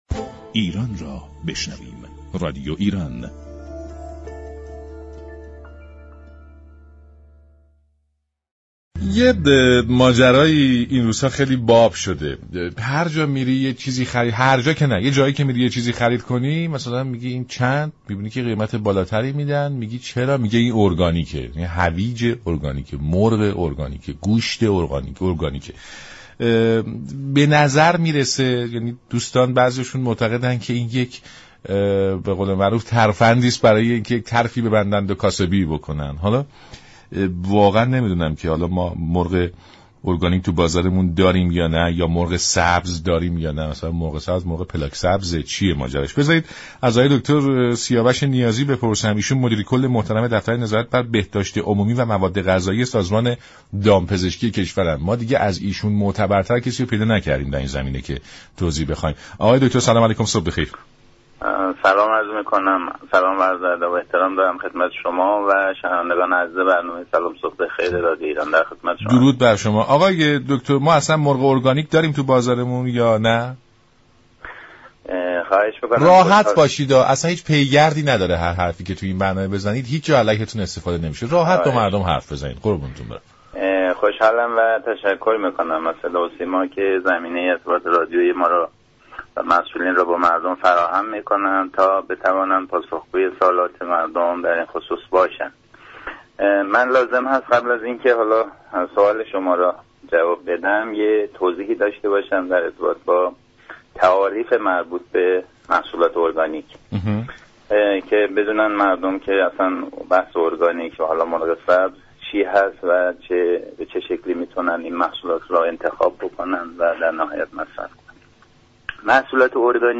در گفت و گو با برنامه «سلام صبح بخیر» به بحث محصولات ارگانیك پرداخت